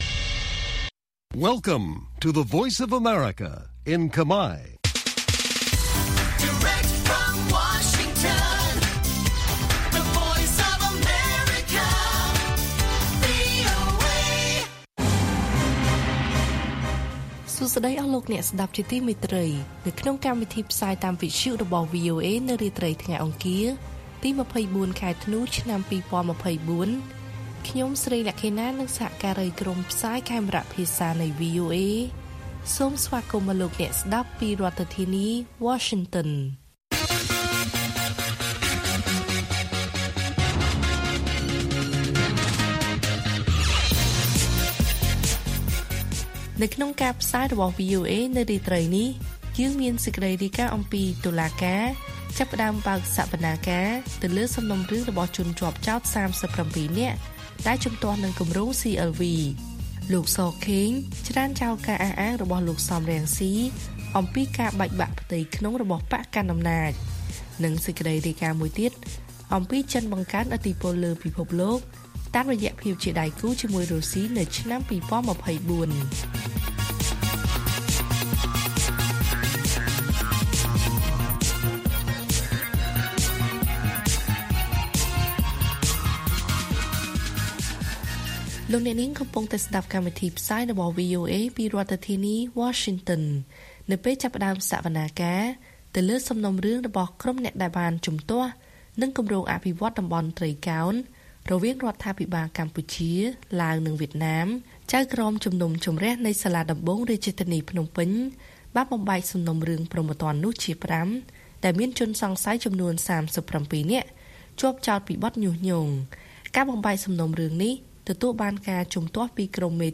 ព័ត៌មានពេលរាត្រី ២៤ ធ្នូ៖ តុលាការចាប់ផ្តើមបើកសវនាការលើសំណុំរឿងរបស់ជនជាប់ចោទ៣៧នាក់ដែលជំទាស់នឹងគម្រោង CLV